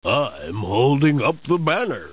00202_Sound_BEAR.mp3